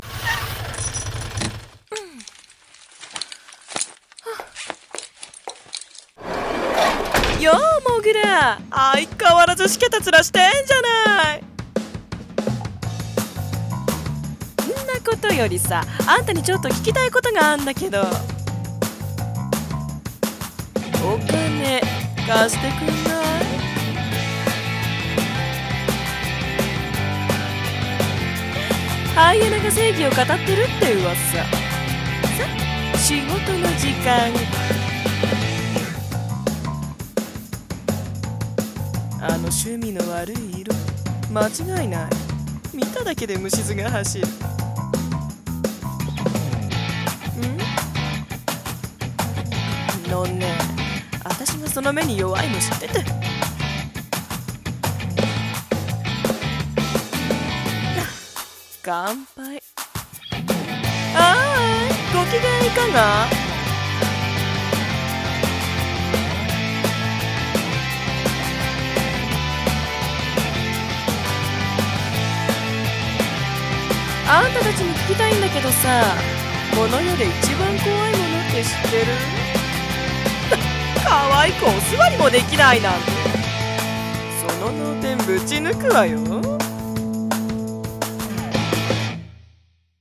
【声劇】GREIFEN!